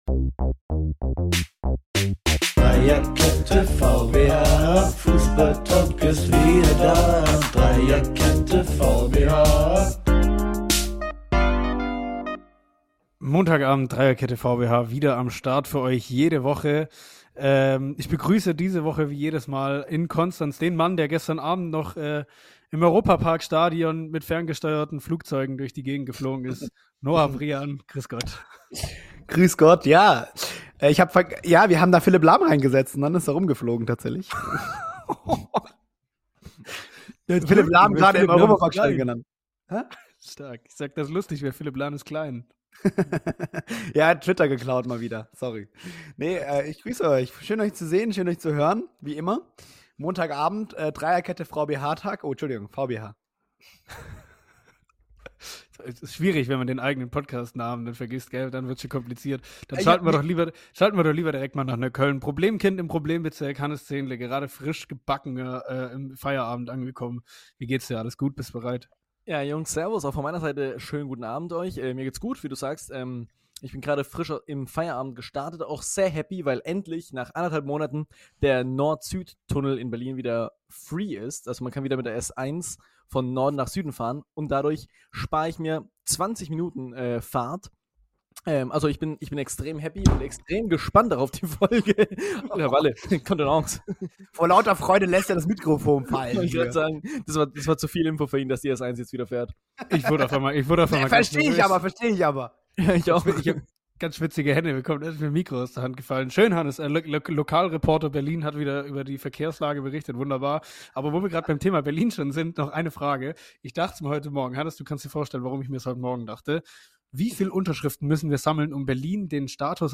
Die wildeste Aufnahme seit Langem! Bei so einer leidenschaftlichen Debatte kann man einfach nicht weghören.